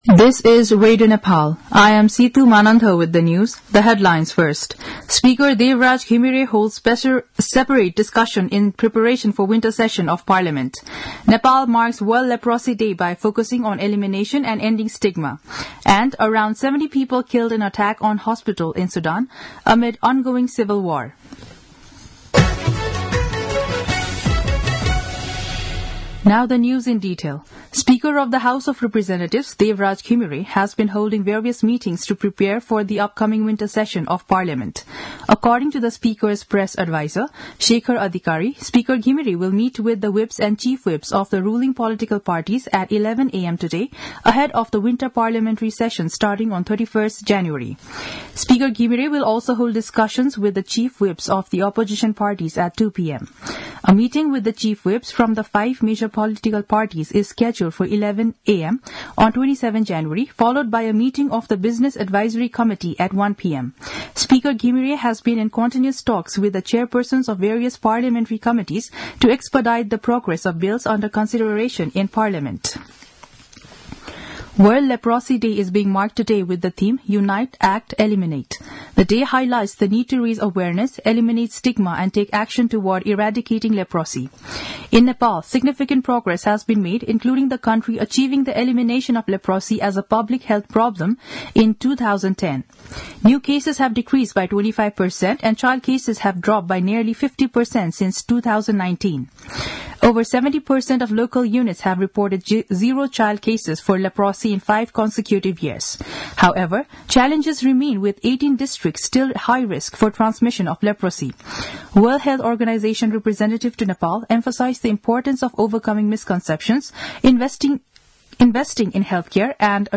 दिउँसो २ बजेको अङ्ग्रेजी समाचार : १४ माघ , २०८१